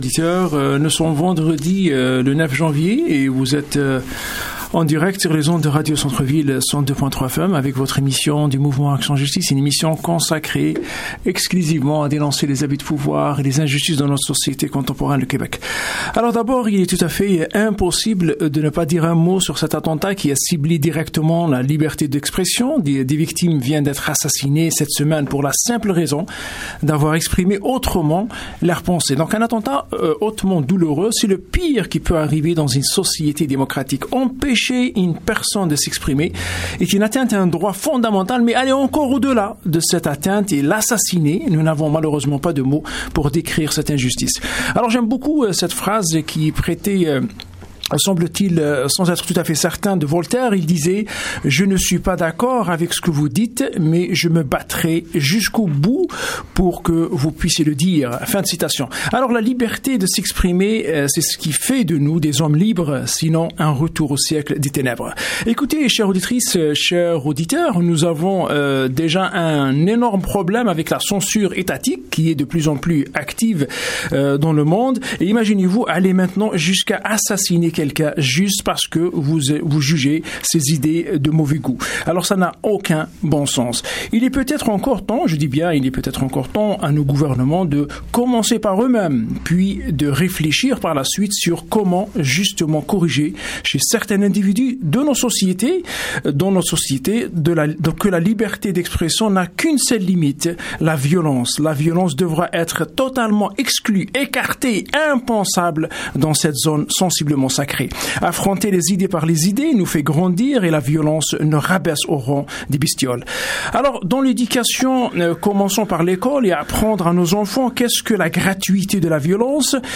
tous réunis autour de la table au studio de Radio Centre-Ville, Montréal